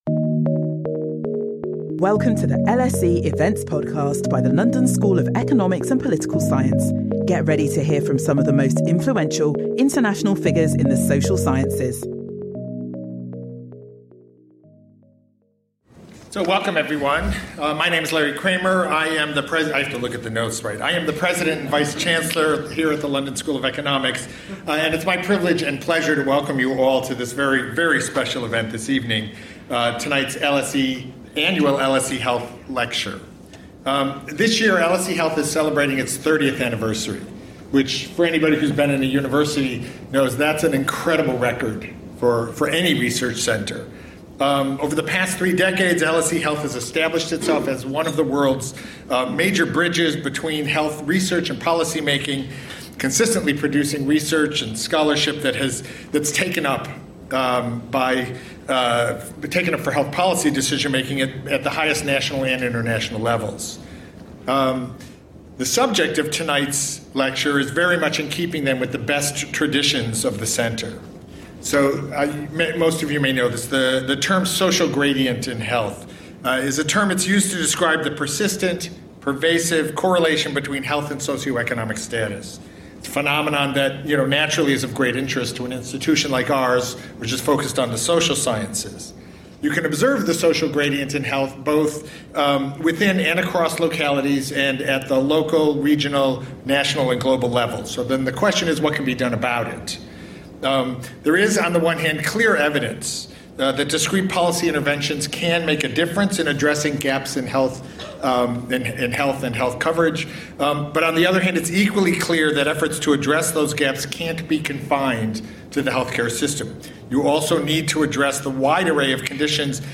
In LSE Health's Annual Lecture, kicking off the centre’s 30th anniversary celebration, Michael Marmot, Professor of Epidemiology at University College London and Director of the UCL Institute of Health Equity, will outline why the need to reduce inequalities in health is a matter of social justice.